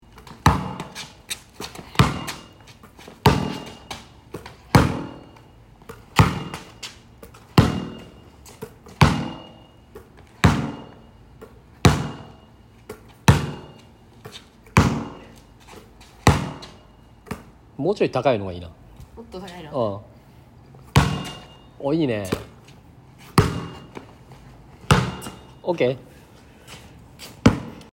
ボールは友達 - バウンド音
ボールの大きさや種類、床の材質によって異なる響きを作ります。
初期反射音がなんとも金属的でカッコいいのですが、
とりわけ僕にはキックに聴こえるわけでマシュー・ハーバートのような曲を作りたくなります！